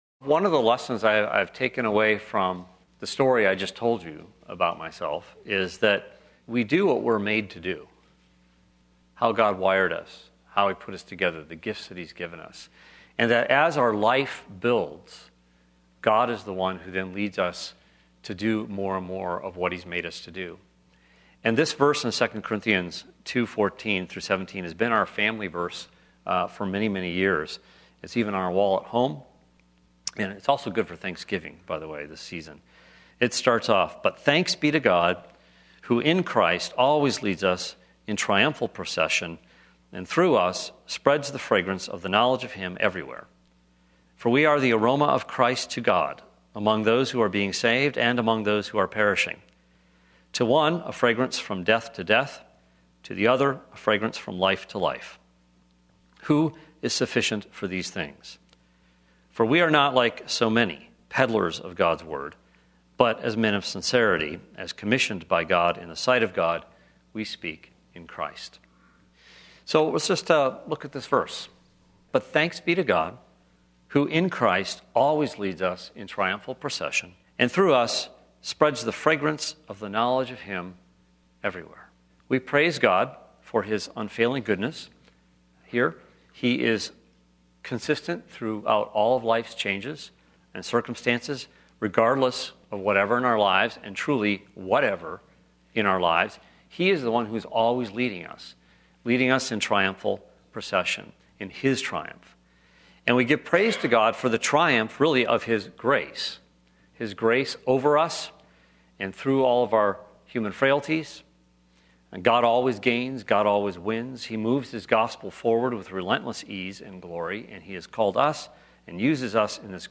Church of the Canyons - Sermons - Santa Clarita - Evangelical Free